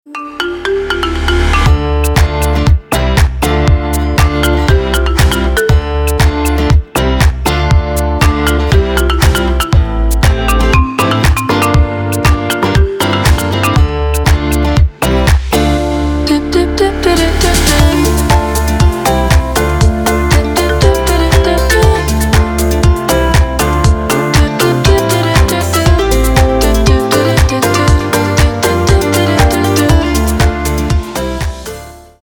• Качество: 256, Stereo
deep house
dance
Electronic
без слов
Melodic